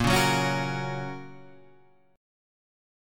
A# Minor Sharp 5th